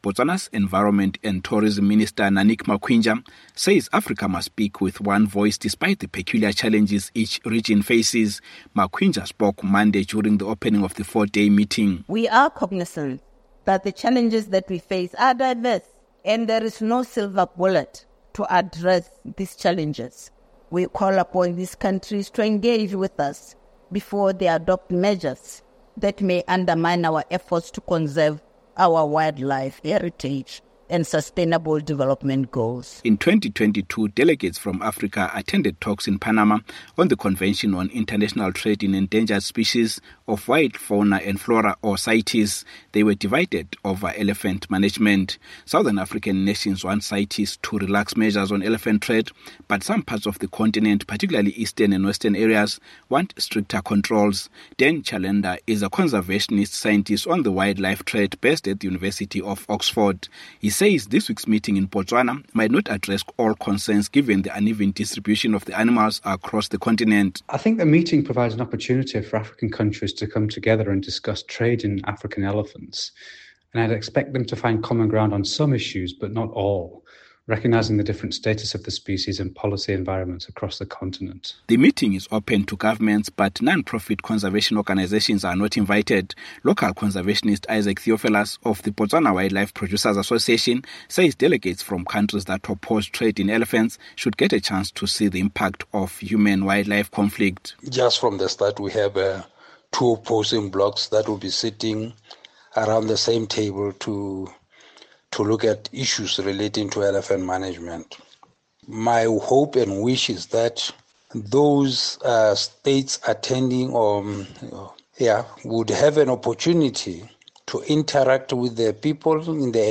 Botswana is hosting 33 African elephant range states for talks on the trade in live elephants. They are also seeking a common position as Africa battles increasing elephant populations in some areas, while elsewhere on the continent, the numbers are declining. From Gaborone